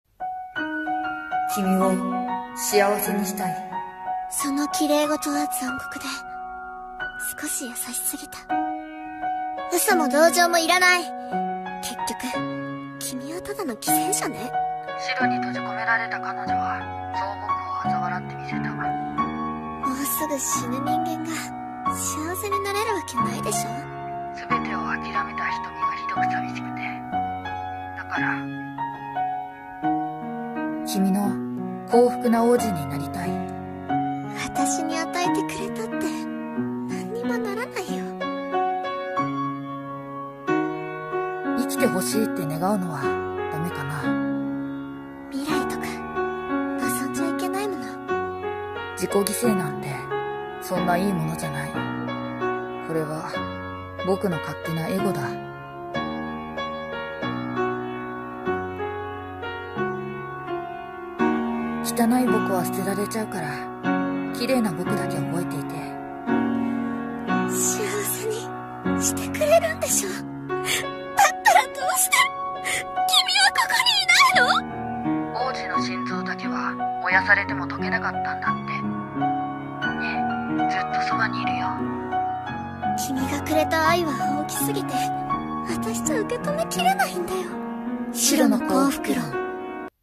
【予告風声劇】シロの幸福論